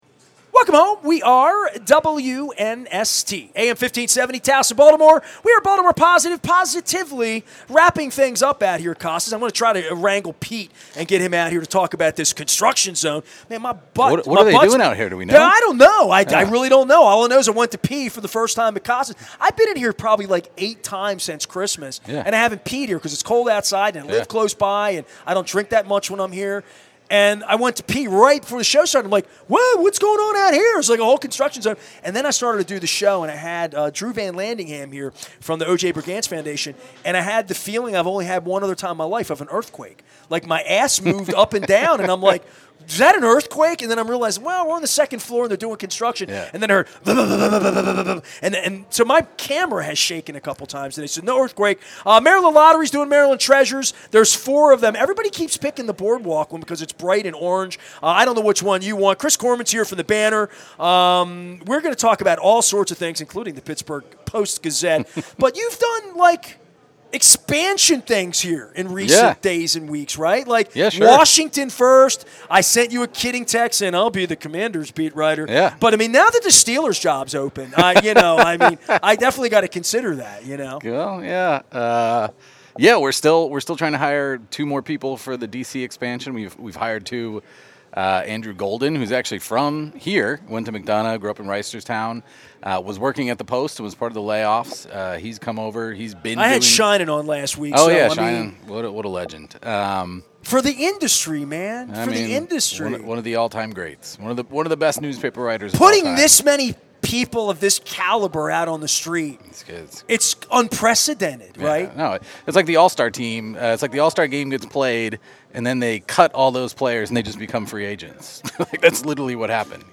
at Costas Inn in Timonium on the Maryland Crab Cake Tour